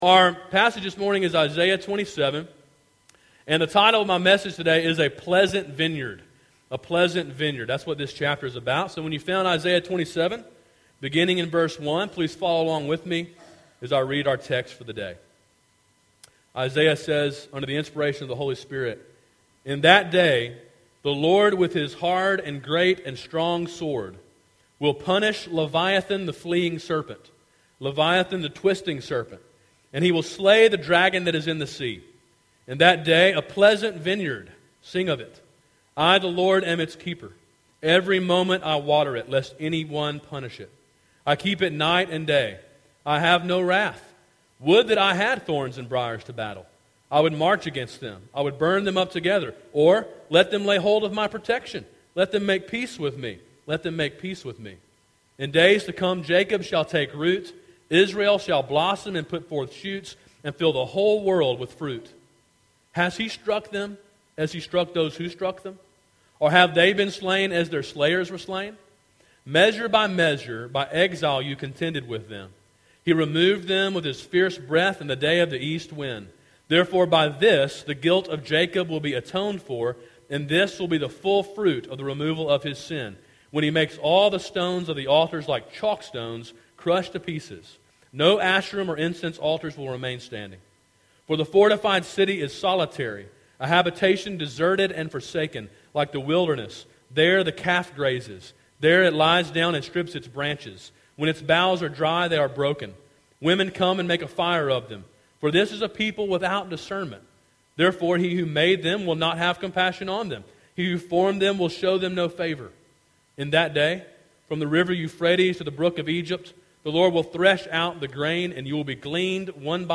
Sermon: “A Pleasant Vineyard” (Isaiah 27)